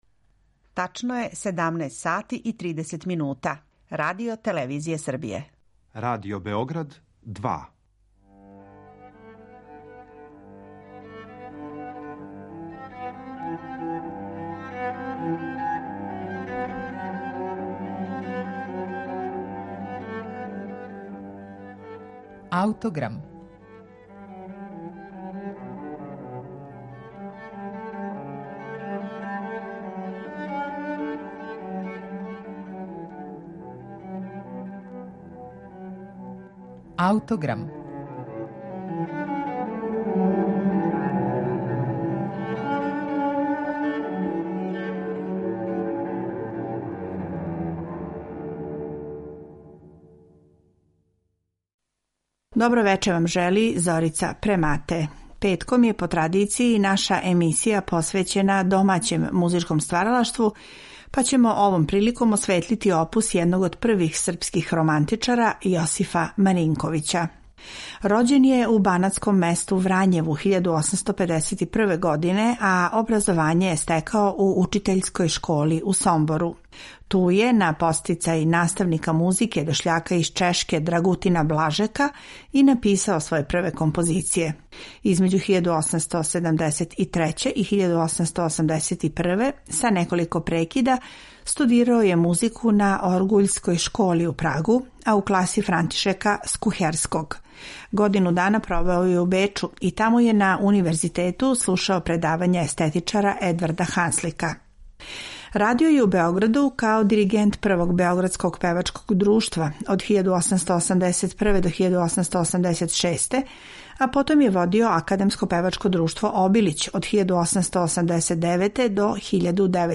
жанр хорова са клавиром. Она је припремила избор из ових његових остварења у верзији за солисте, хор и оркестар. Са наших архивских снимака слушаћете „Поточару", „Задовољну реку" и „На велики петак", као и неколико Маринковићевих соло-песама.